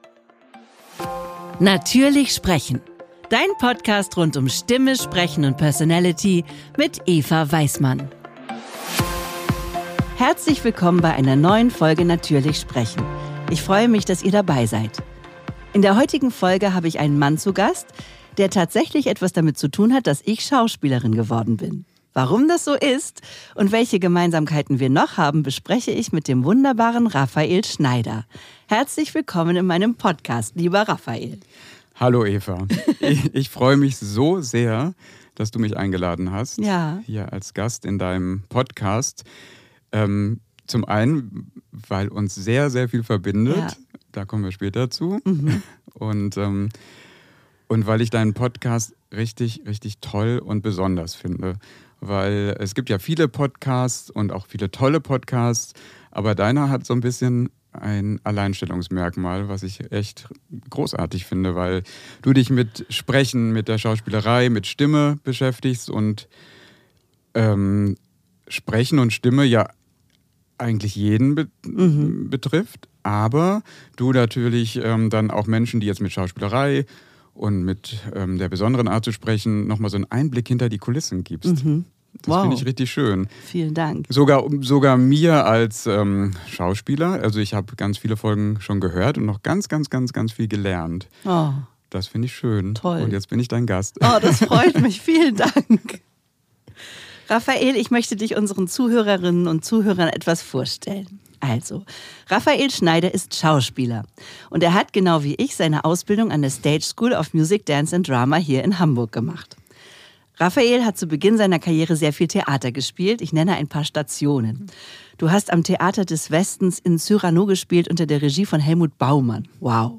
Lasst euch mit hineinnehmen in dieses schöne Gespräch.